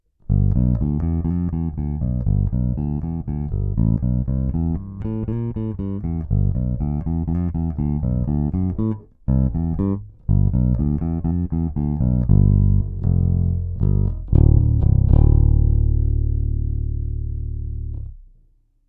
Snímač krk, dvoucívka, sériově (prsty)